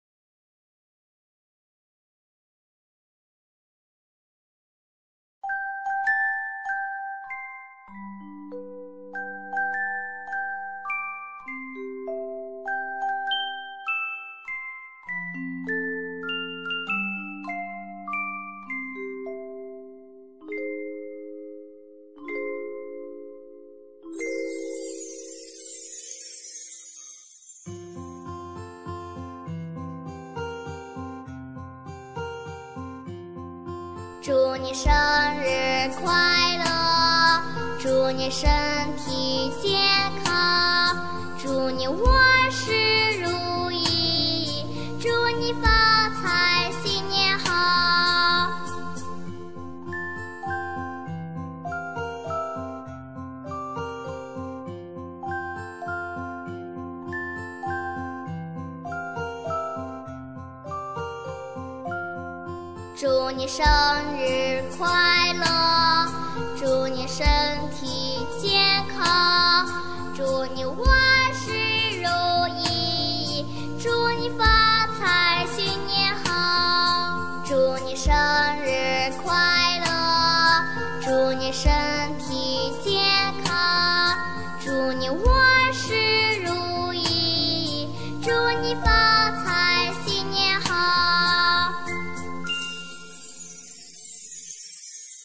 دانلود آهنگ هپی برث دی خارجی چینی با صدای بچگانه